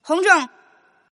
Index of /client/common_mahjong_tianjin/mahjongjinghai/update/1124/res/sfx/tianjin/woman/